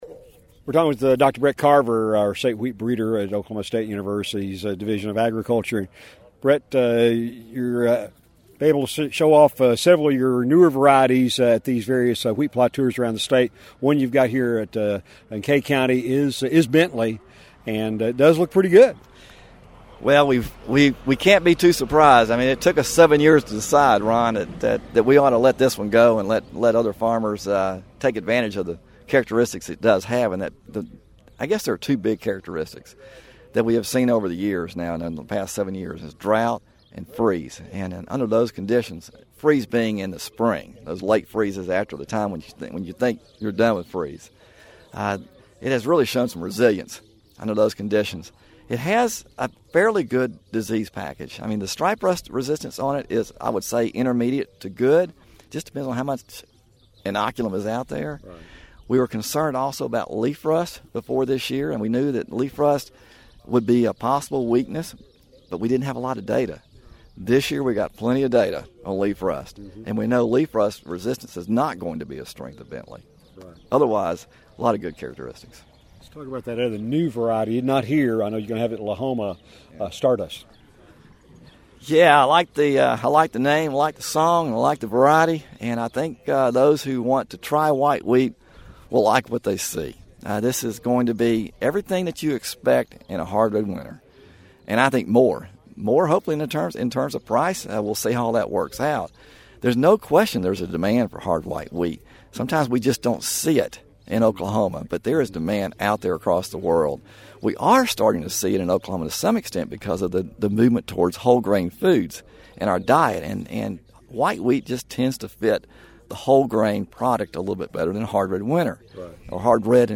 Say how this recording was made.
You can hear their full conversation from the wheat plots in Kay County on Wednesday- click on the LISTEN BAR below to hear their Q&A.